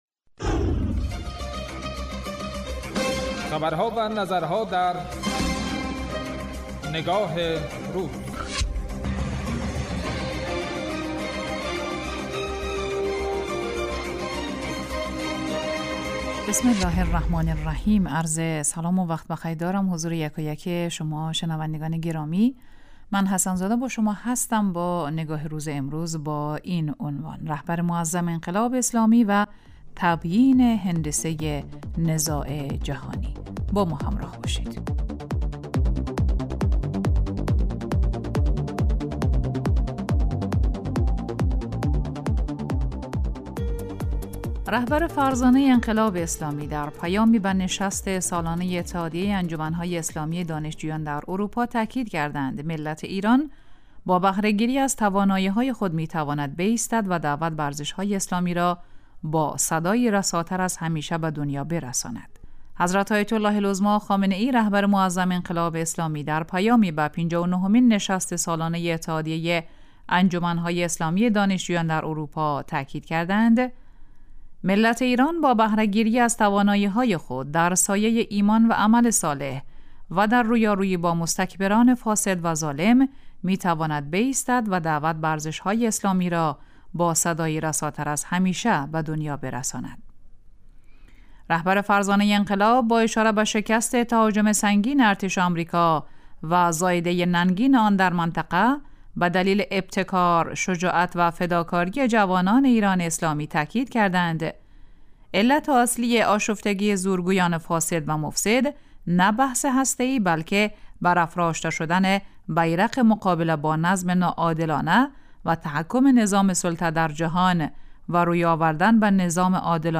رهبر انقلاب با اشاره به شکست تهاجم سنگین ارتش آمریکا و زائده‌ی ننگین آن در منطقه به دلیل ابتکار و شجاعت و فداکاری جوانان ایران اسلامی، تاکید کردند: علت اصلی آشفتگی زورگویان فاسد و مفسد، نه بحث هسته‌ای، بلکه برافراشته شدن پرچم مقابله با نظم ناعادلانه و تحکم نظام سلطه در جهان و روی آوردن به نظام عادلانه‌ی ملی و بین‌المللی اسلامی از جانب ایران اسلامی است. برنامه تحلیلی نگاه روز از شنبه تا پنجشنبه راس ساعت 14 به وقت کابل به مدت 10 دقیقه به وقت افغانستان پخش می گردد.